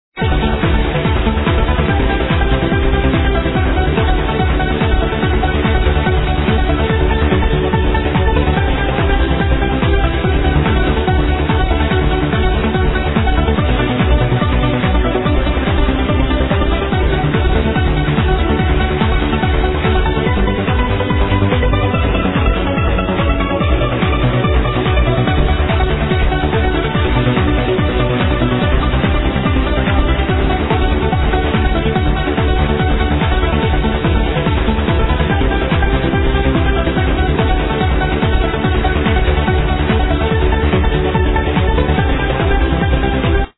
best trance track ive ever heard.